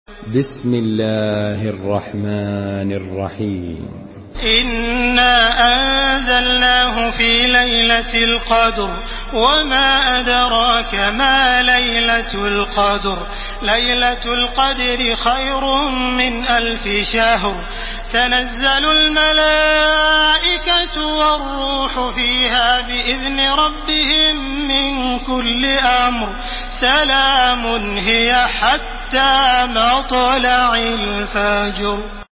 تحميل سورة القدر بصوت تراويح الحرم المكي 1431
مرتل